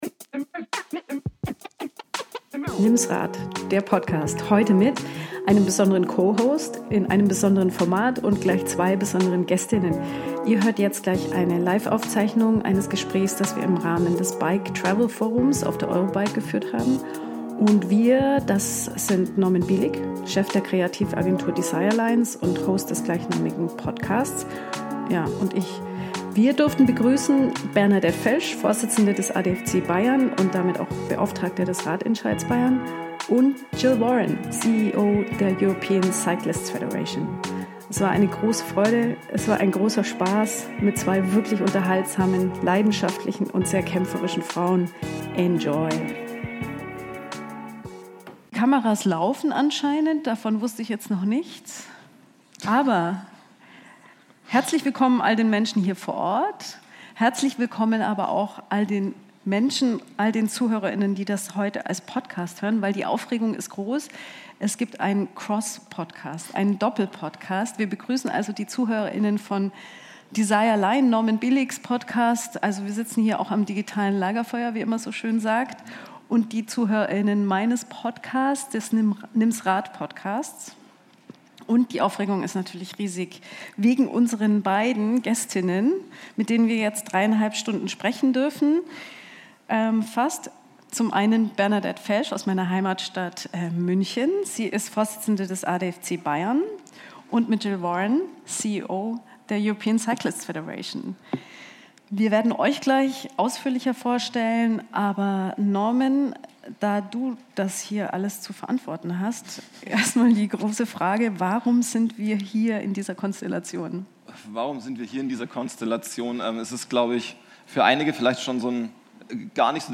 Podcast-Special von der Eurobike 2023: Von Brüssel bis Bayern – Wie geht Fahrradpolitik? ~ Nimms Rad Podcast